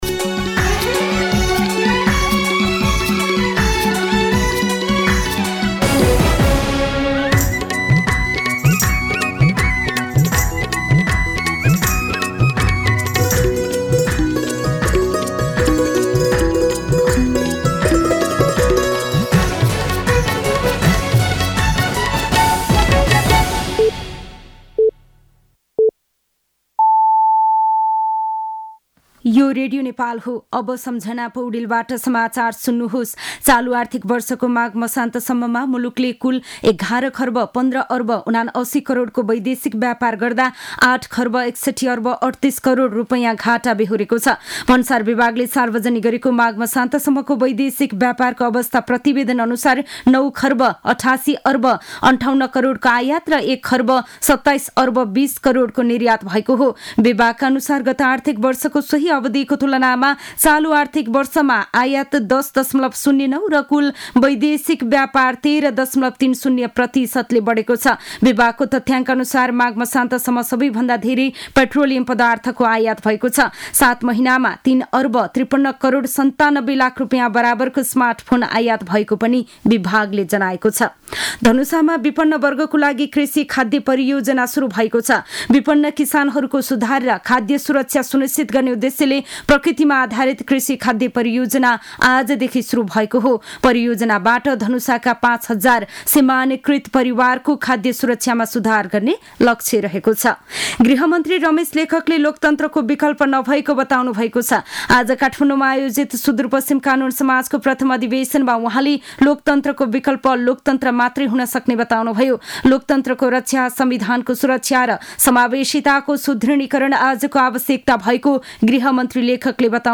दिउँसो ४ बजेको नेपाली समाचार : ११ फागुन , २०८१
4-pm-news-7.mp3